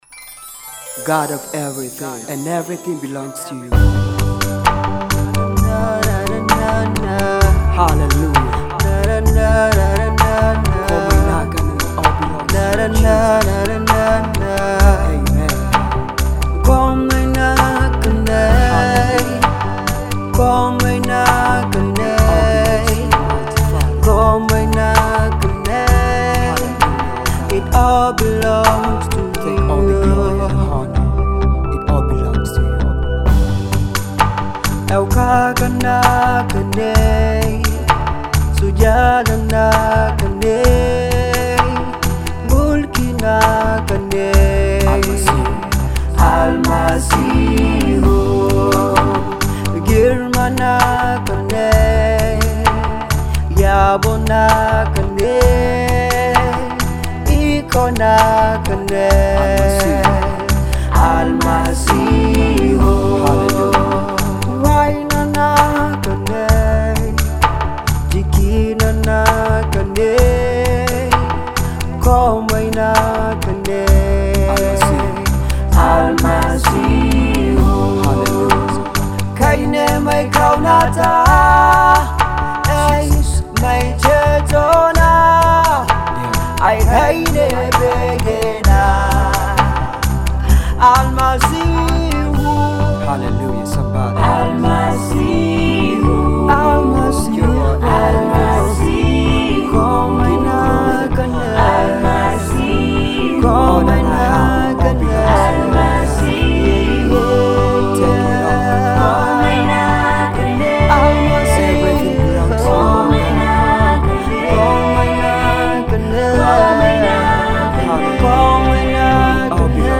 This song is a simple and meaning full worship song.